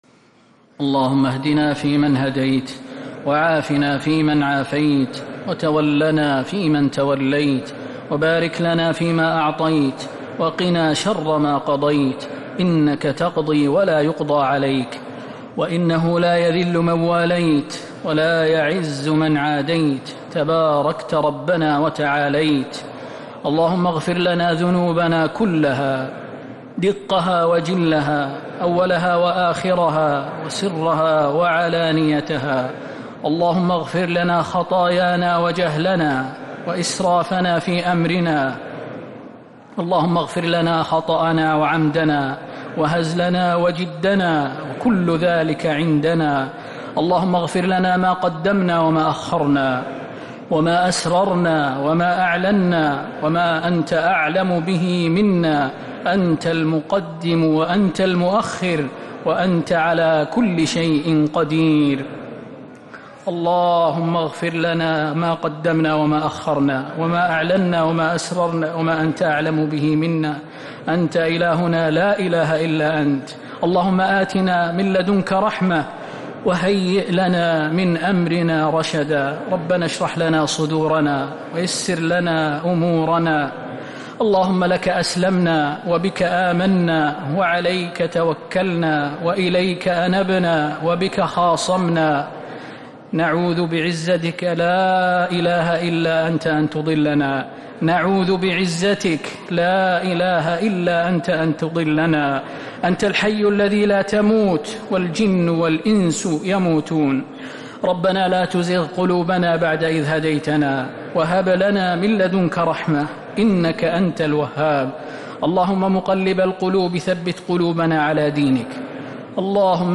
دعاء القنوت ليلة 7 رمضان 1447هـ | Dua 7th night Ramadan 1447H > تراويح الحرم النبوي عام 1447 🕌 > التراويح - تلاوات الحرمين